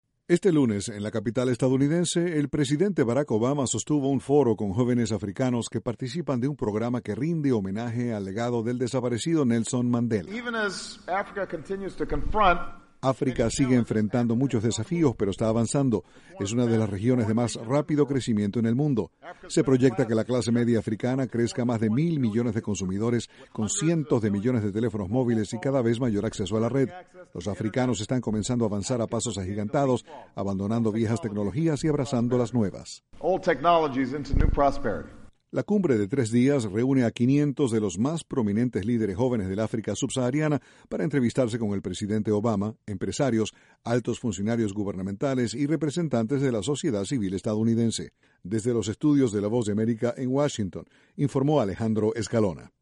El presidente Barack Obama dijo que el continente africano está avanzando a pasos agigantados en materia de tecnología. Desde la Voz de América, Washington